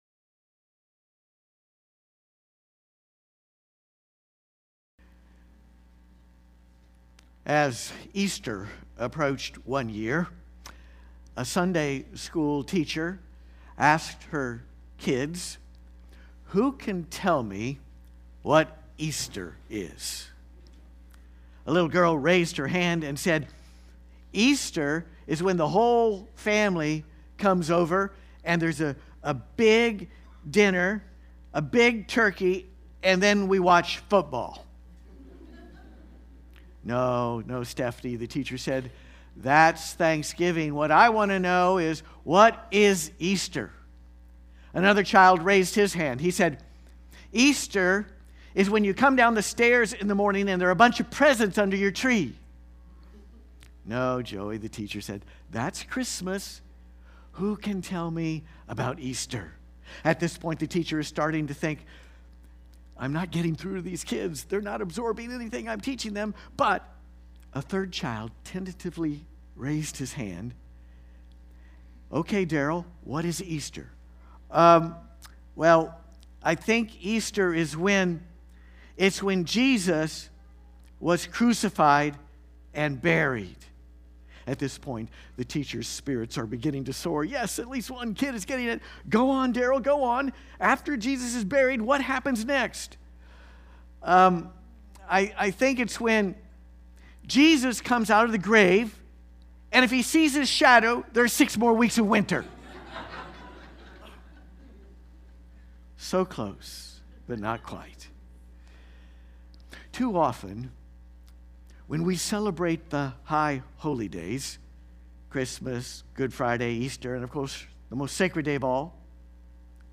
Join us this week for our Easter Celebration as we remind ourselves what Jesus' resurrection means for us now and challenge ourselves to live in that power and awareness.